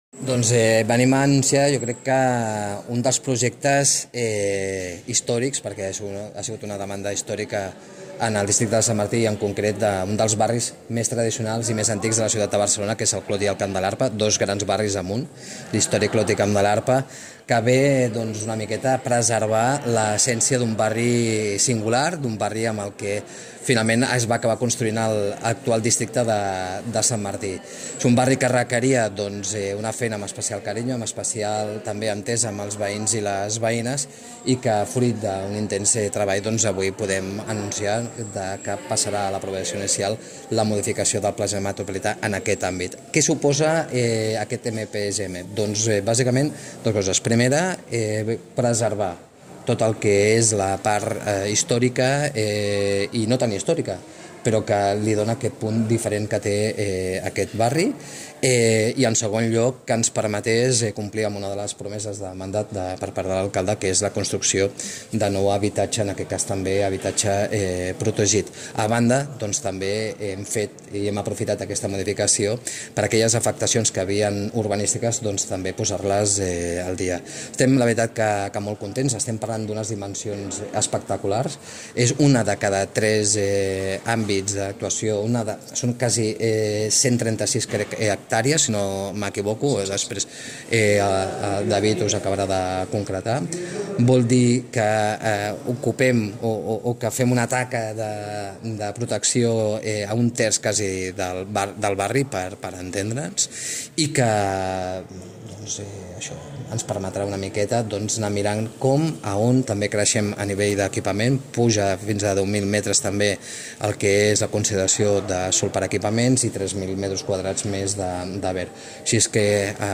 Declaración de David Escudé